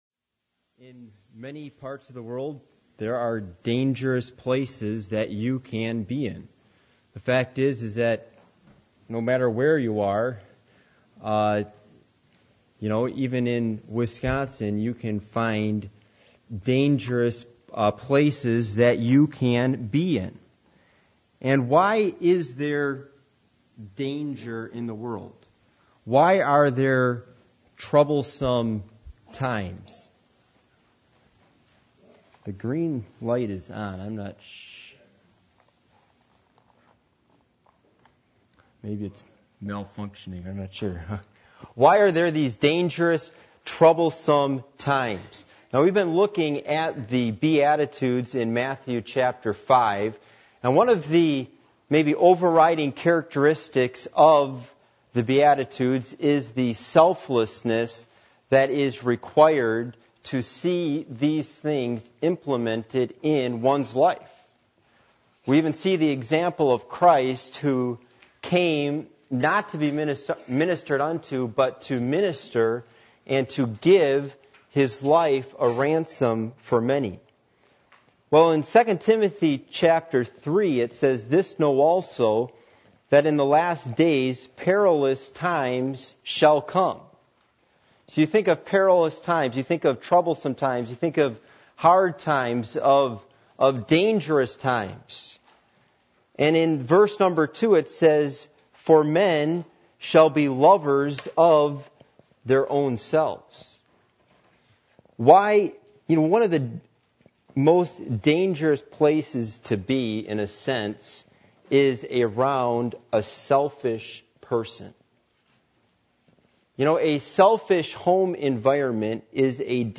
Passage: 2 Timothy 3:1-7 Service Type: Midweek Meeting %todo_render% « Are We Losing Our Christianity?